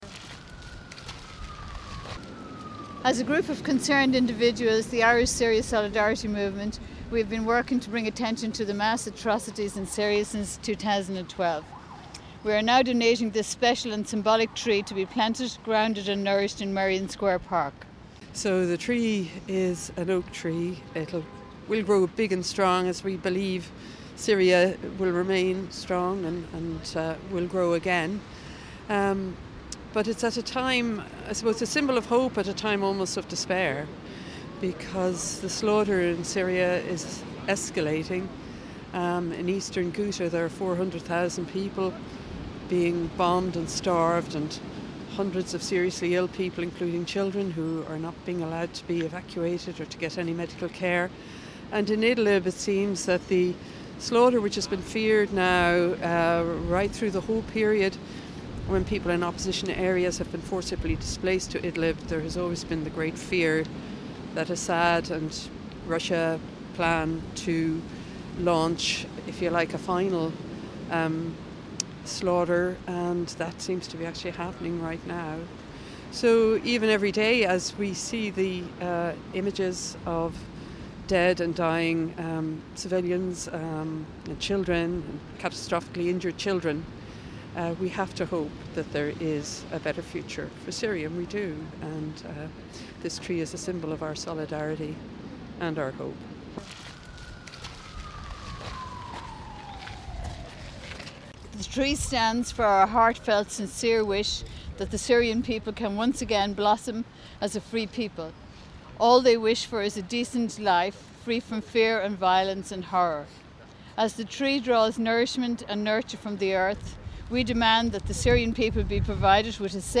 In Dublin's Merrion Square the Irish Syria Solidarity Movement planted an oak tree to celebrate the White Helmets winning the latest International Tipperary Peace Prize. However, the continuing slaughter of civilians and the White Helmets in Idlib and East Ghouta was very much the main focus of the occasion.